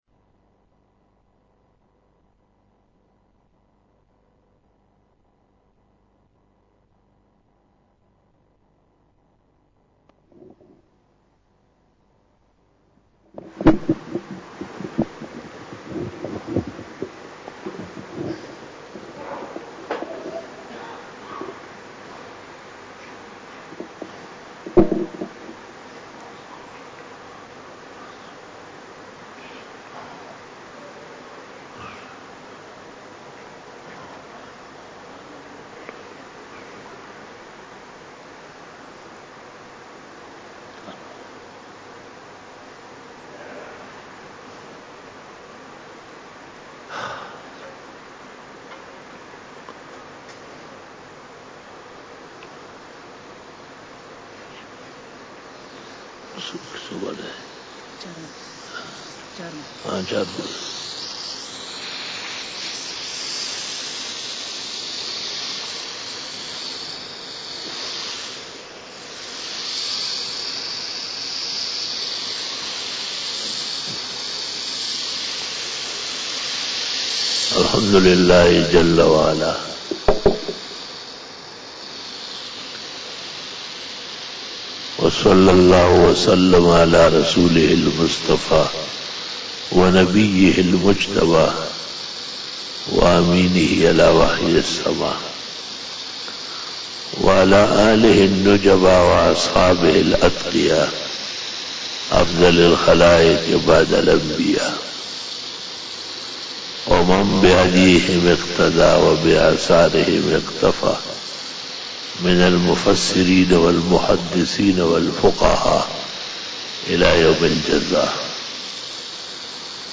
11 BAYAN E JUMA TUL MUBARAK 13 March 2020 (17 Rajab 1441H)